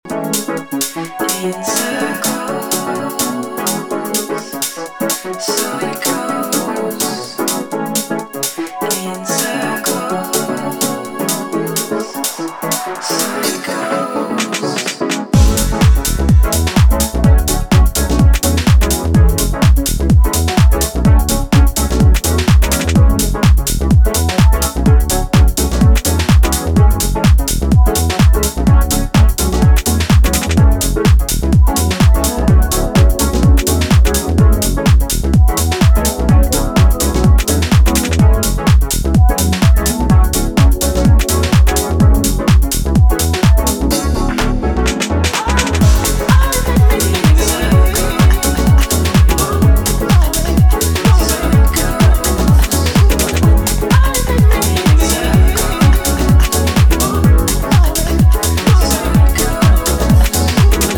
garage and tribal house